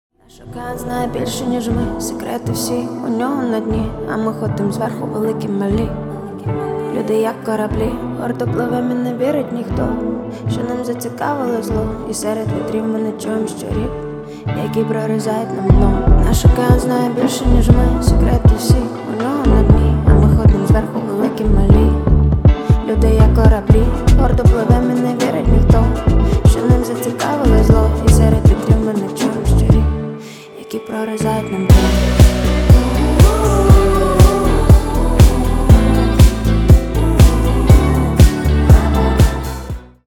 Поп Музыка
грустные # спокойные # тихие